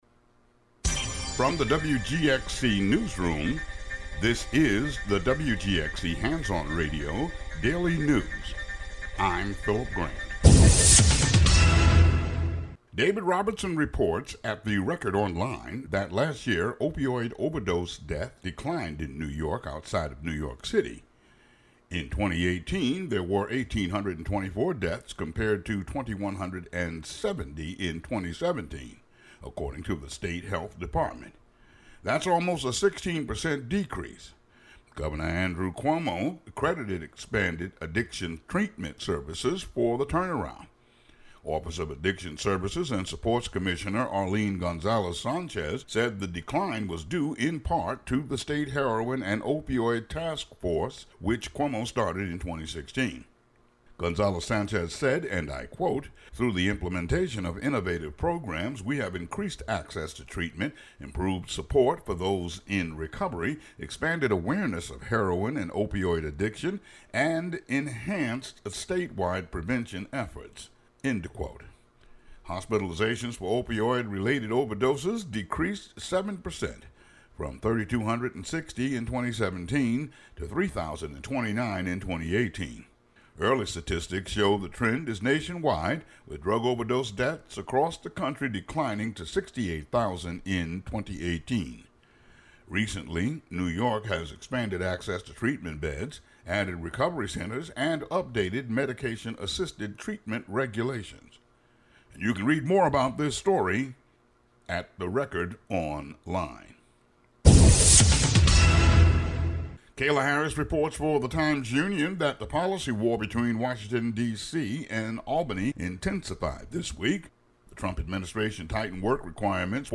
The audio version of the local news for Wed., Dec. 11.